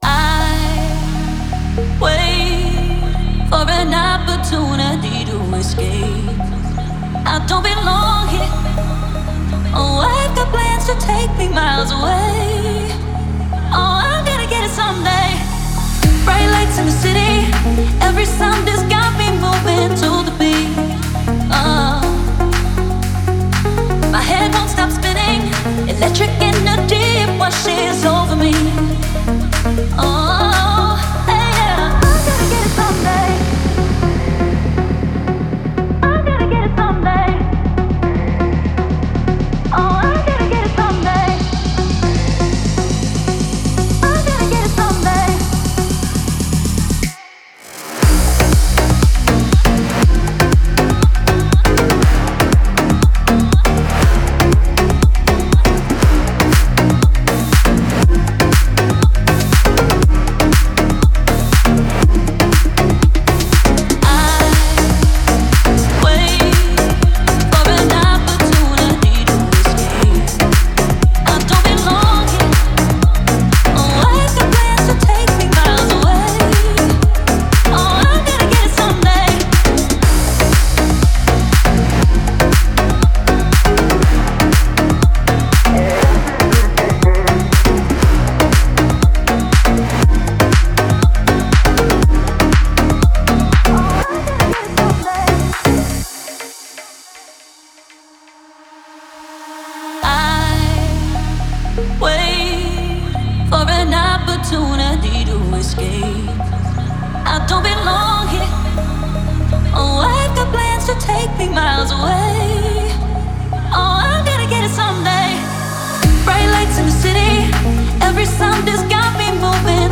который сочетает в себе элементы поп и инди-рока.